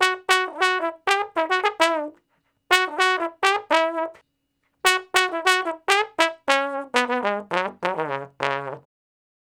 099 Bone Straight (Db) 07.wav